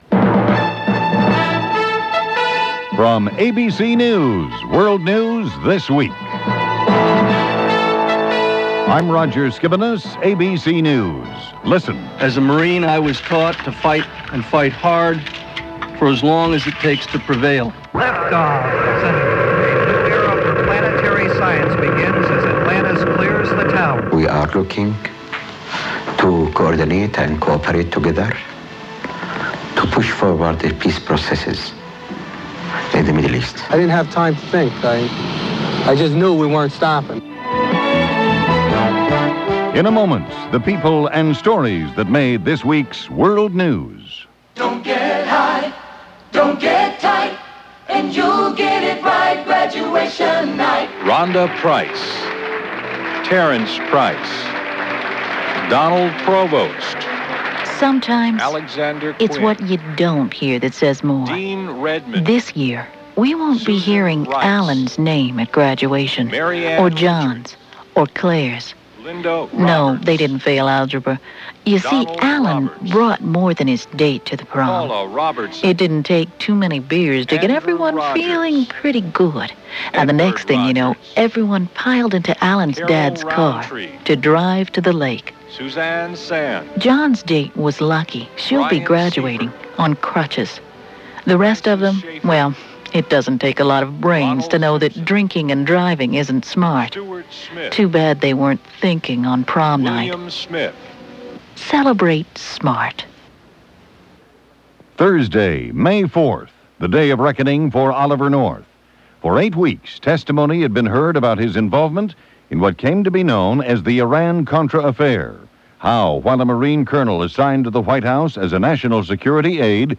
News-for-Week-of-May-7-1989.mp3